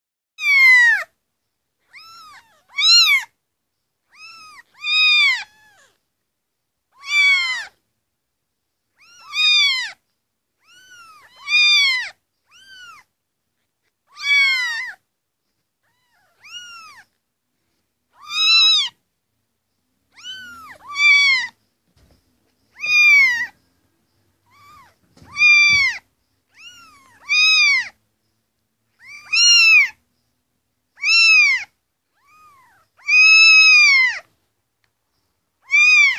Мяуканье котят чтобы привлечь кошку